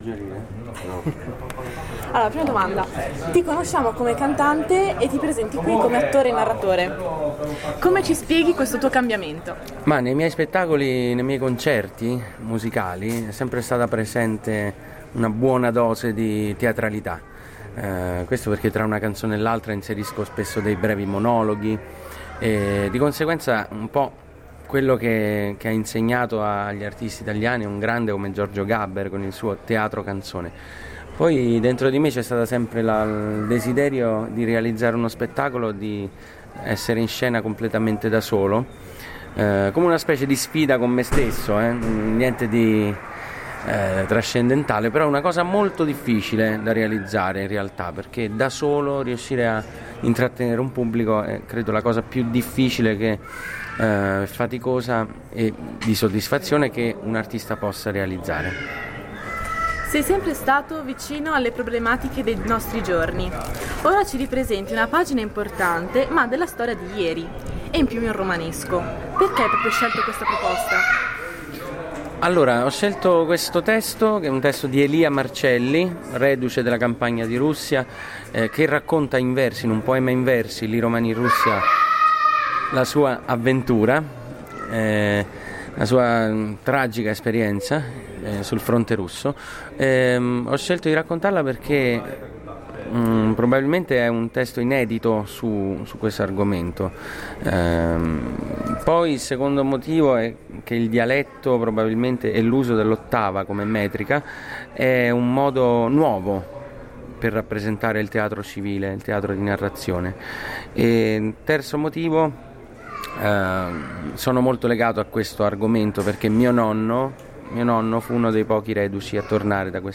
Intervista a Simone Cristicchi